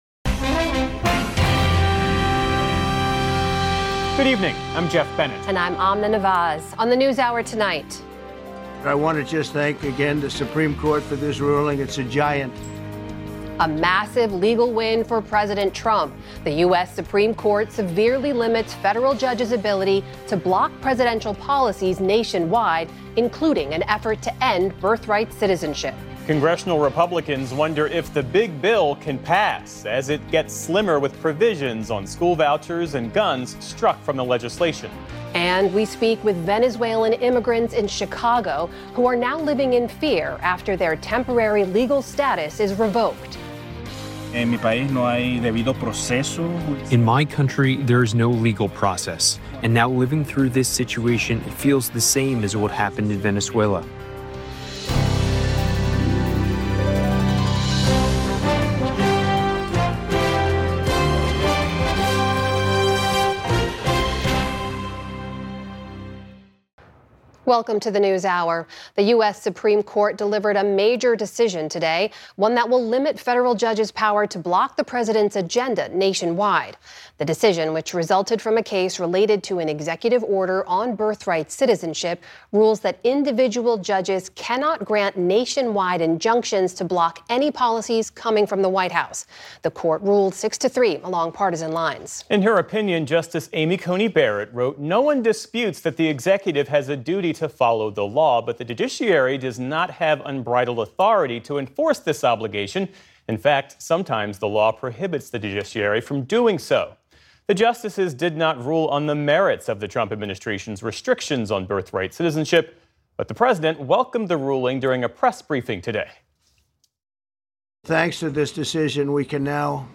Congressional Republicans wonder if Trump’s “big bill” can pass as it gets slimmer. Plus, we hear from Venezuelan immigrants in Chicago who are now living in fear after their temporary protected status was revoked.